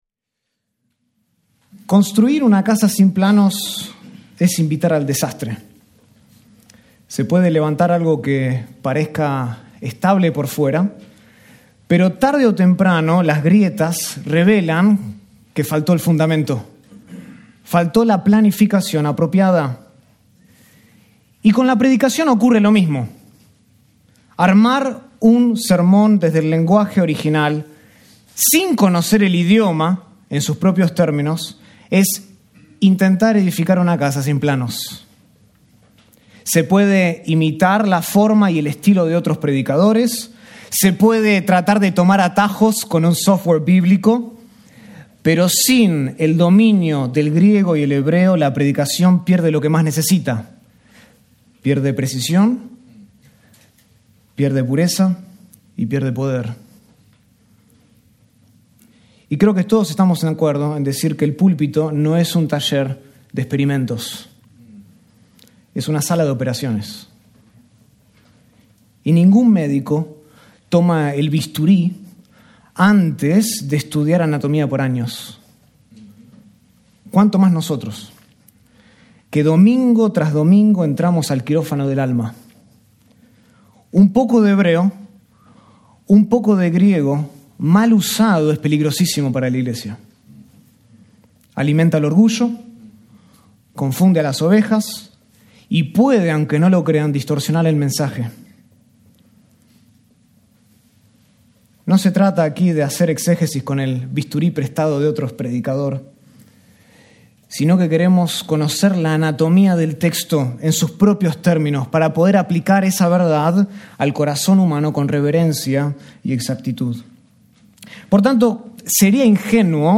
Taller: Preparar un sermón desde el idioma original | Conferencia Expositores | Grace Community Church